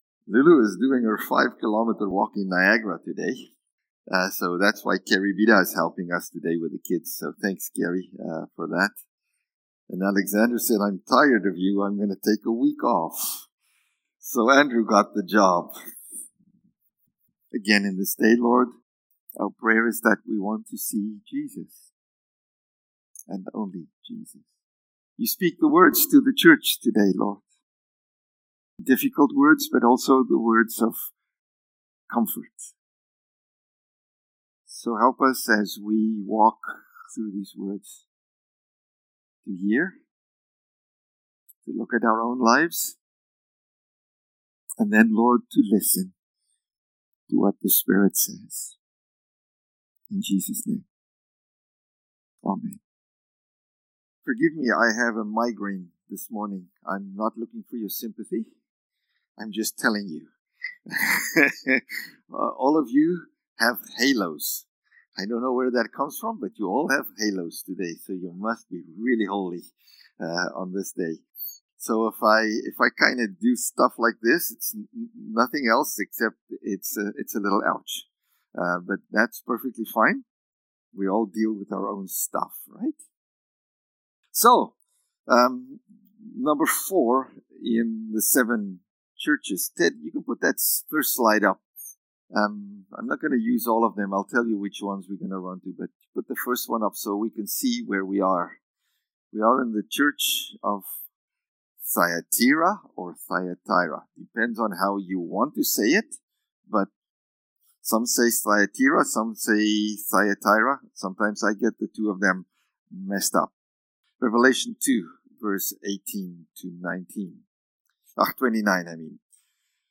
November-2-Sermon.mp3